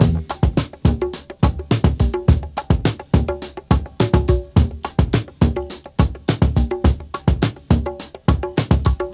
105 Bpm Drum Loop E Key.wav
Free drum loop - kick tuned to the E note. Loudest frequency: 2216Hz
105-bpm-drum-loop-e-key-yPD.ogg